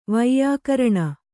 ♪ vaiyākaraṇa